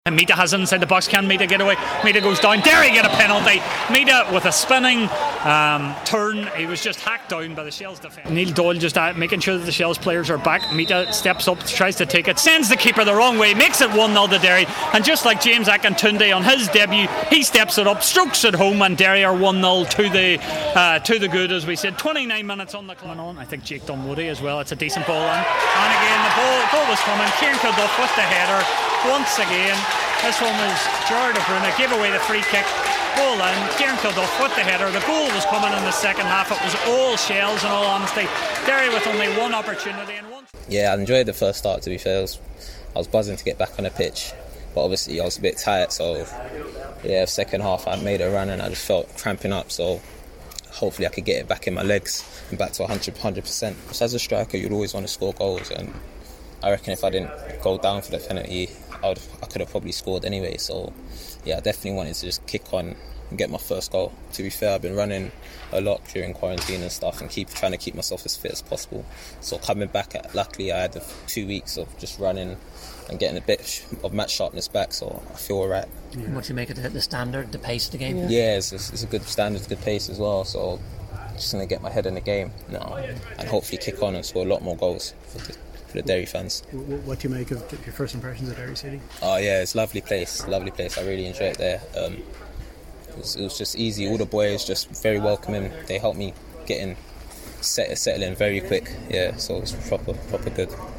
Match Commentary